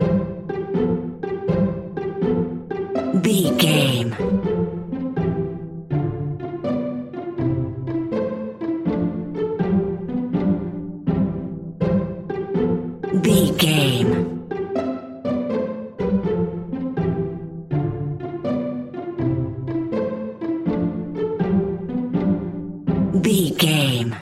Ionian/Major
Slow
childrens music
glockenspiel